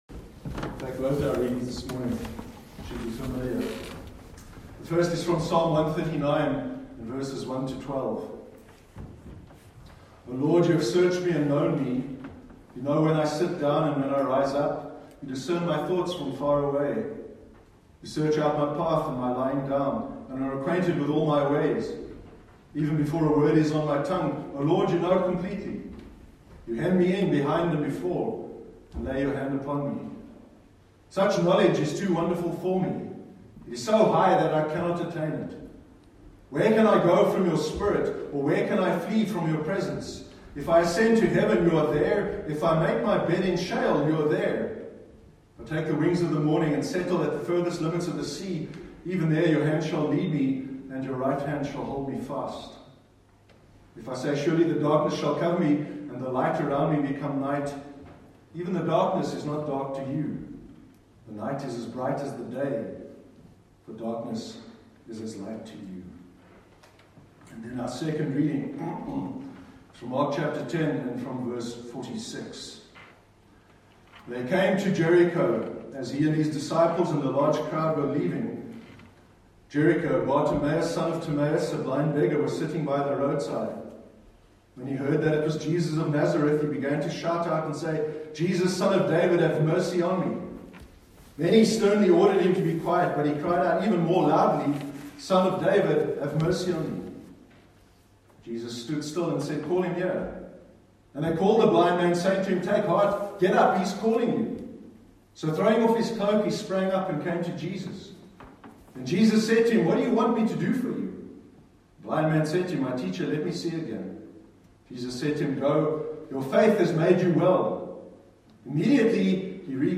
Practising Presence- Sermon 27th Oct 2019